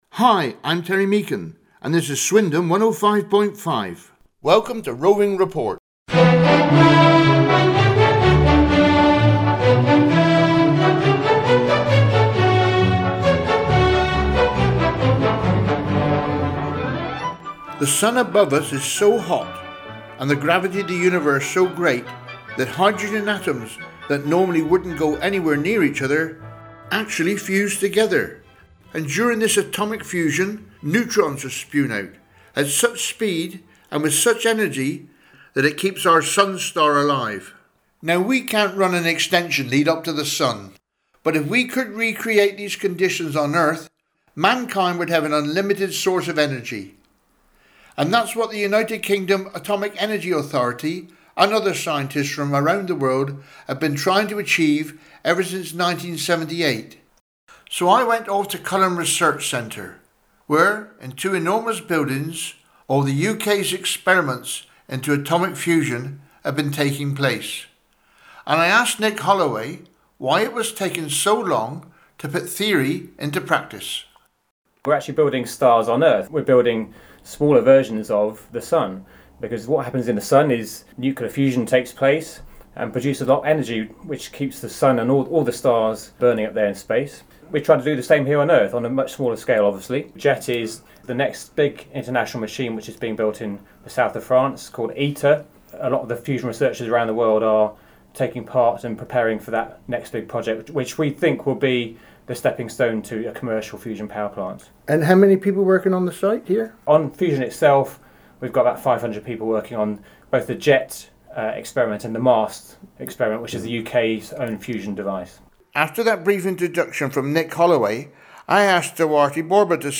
Find out in this fascinating investigation recorded live at UK Atomic Centre for Fusion Energy. ⏱ Running time: 28 minutes This programme is a Roving Report Special for Community Radio with no adverts and no Station ID in the ready-to-broadcast files.